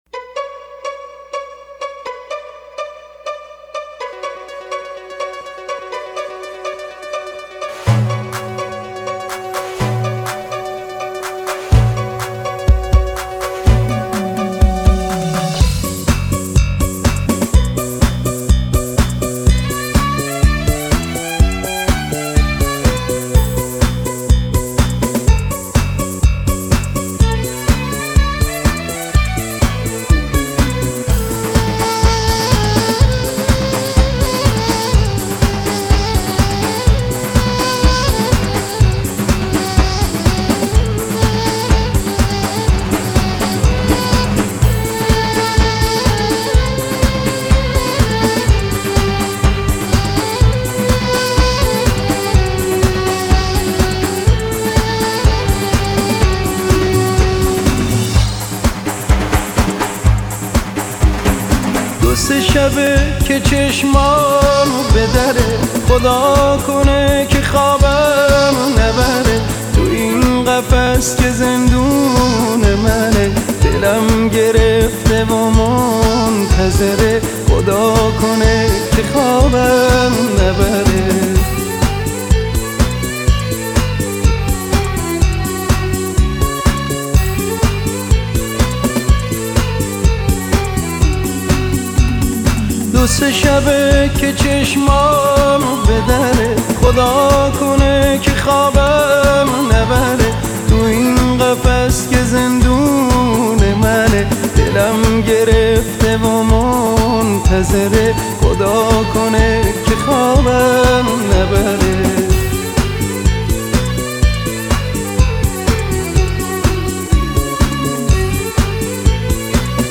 نوع آهنگ : آهنگ قدیمی بسیار زیبا